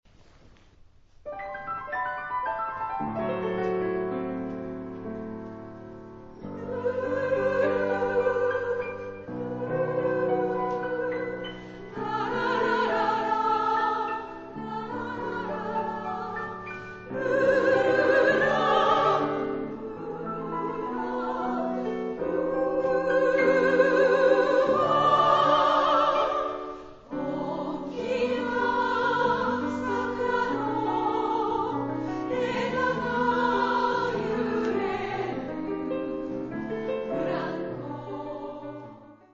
ライブ録音ＣＤ-R
中級用女声３部合唱曲集で，構成はソプラノ，メゾ･ソプラノ，アルトです。
歌いやすいメロディーとハーモニーで，親しみやすい女声合唱曲集に仕上がっています。